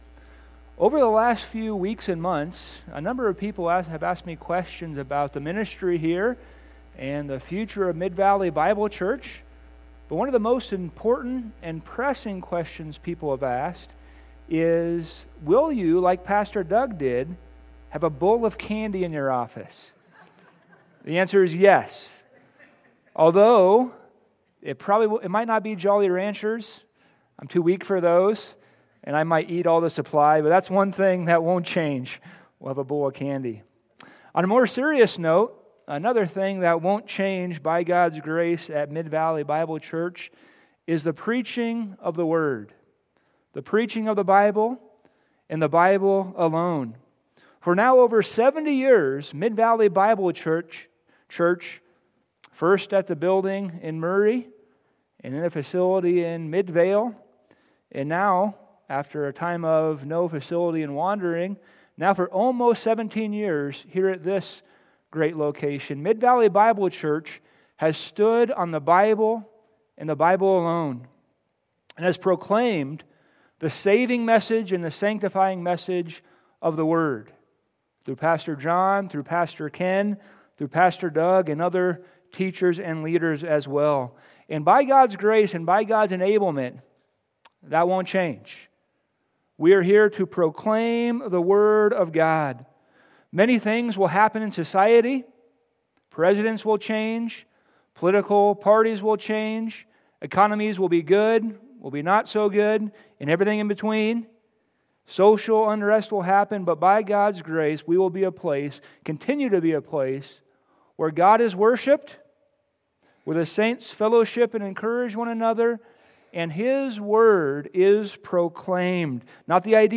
We will begin a new sermon series on God's Plan for the Church.